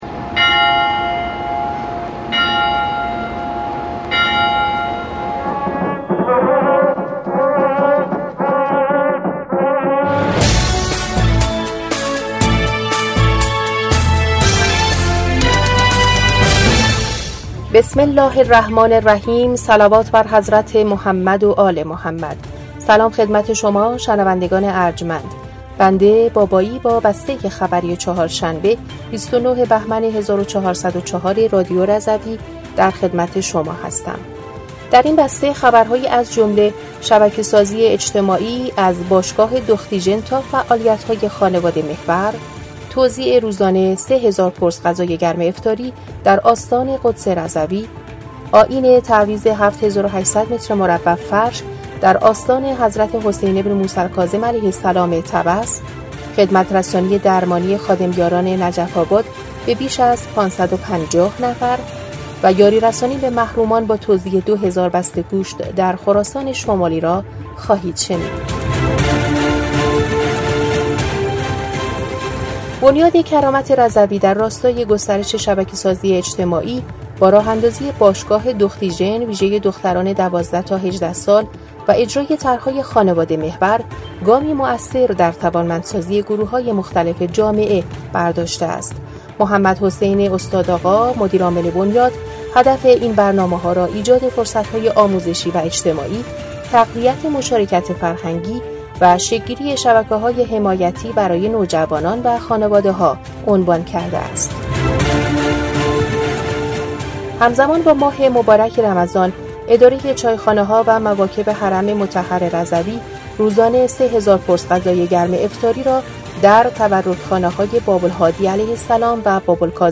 بسته خبری ۲۹ بهمن ۱۴۰۴ رادیو رضوی؛